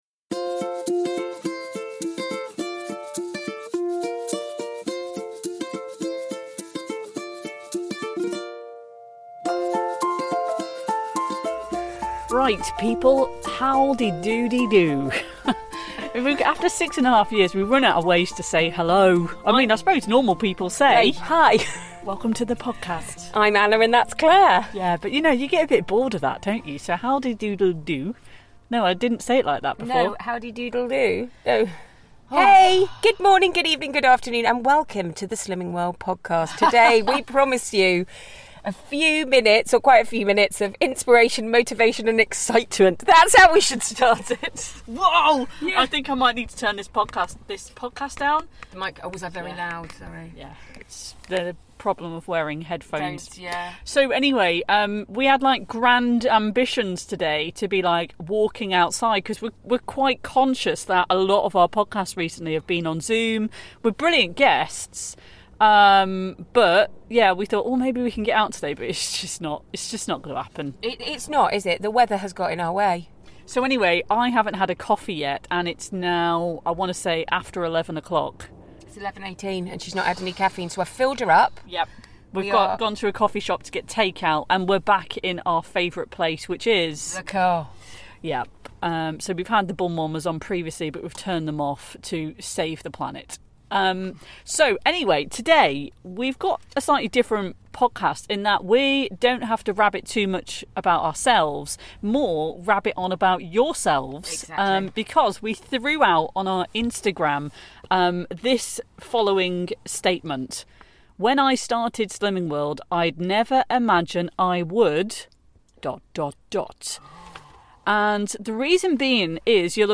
a handful of Slimming World members who share their strategies and inspiration for staying on track when things get tough!